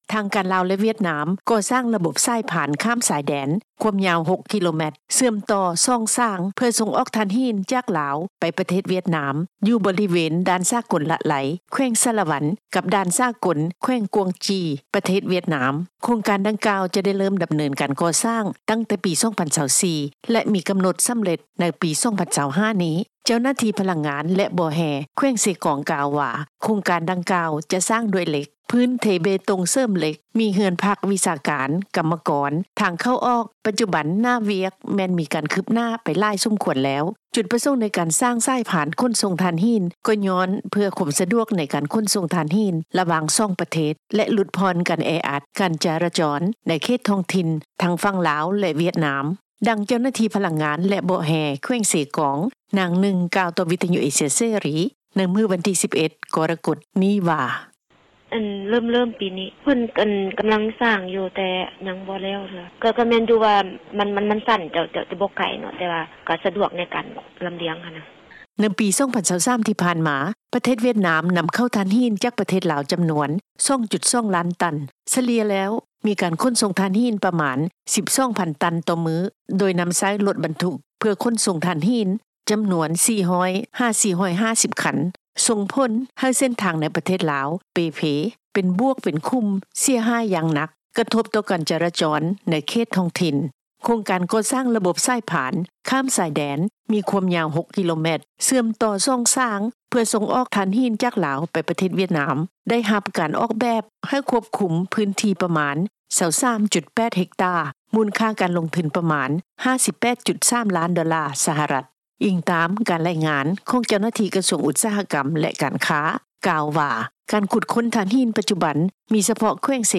ດັ່ງ ເຈົ້າໜ້າທີ່ ພະລັງງານ ແລະ ບໍ່ແຮ່ ແຂວງເຊກອງ ນາງໜຶ່ງ ກ່າວຕໍ່ ວິທຍຸ ເອເຊັຽເສຣີ ໃນມື້ວັນທີ 11 ກໍລະກົດ ນີ້ວ່າ:
ດັ່ງ ປະຊາຊົນລາວ ທ່ານນີ້ ກ່າວຕໍ່ ວິທຍຸ ເອເຊັຽເສຣີ  ໃນມື້ດຽວກັນນີ້ວ່າ: